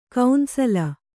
♪ kaunsala